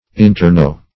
Interknow \In`ter*know"\